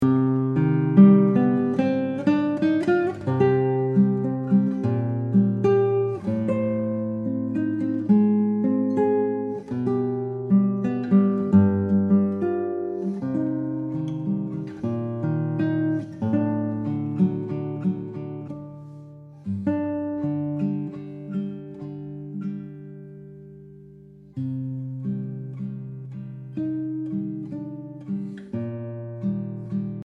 Obras para guitarra